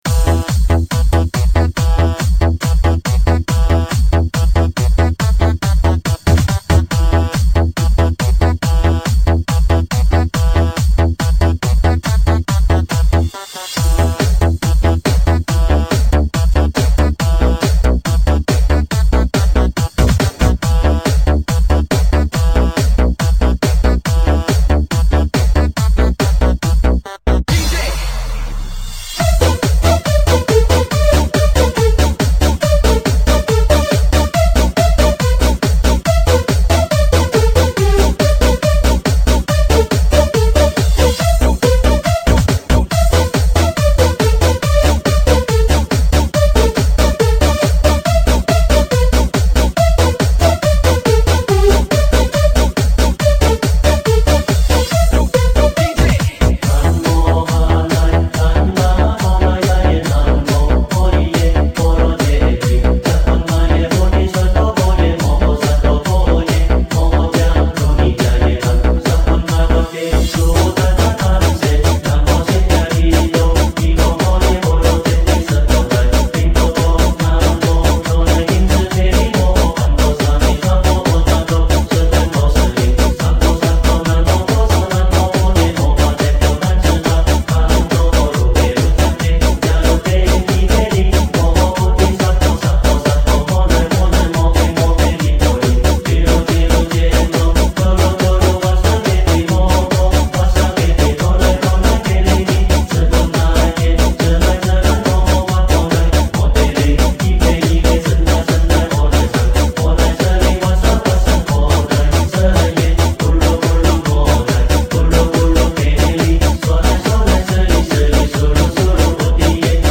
佛音 诵经 佛教音乐 返回列表 上一篇： 般若波罗密多心经 下一篇： 心经-付嘱 相关文章 大慈大悲观世音菩萨--佛教音乐 大慈大悲观世音菩萨--佛教音乐...